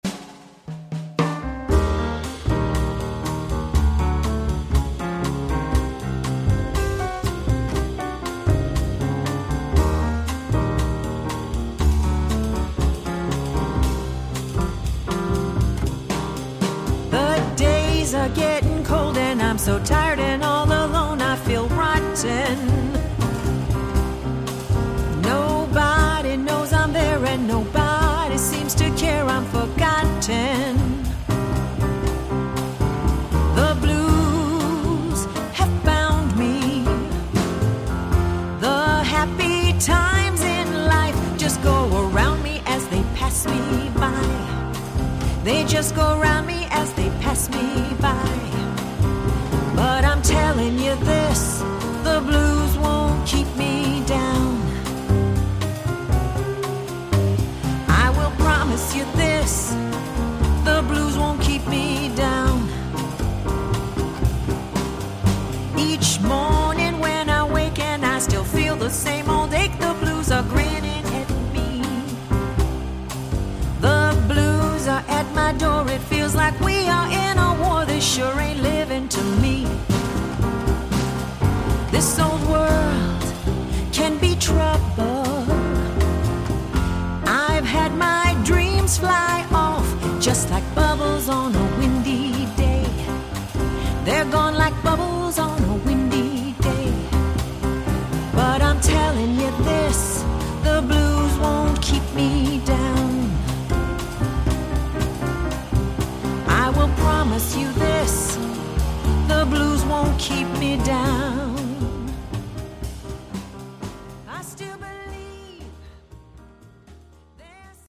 Another album of light jazz, blues, ballads, and bossas.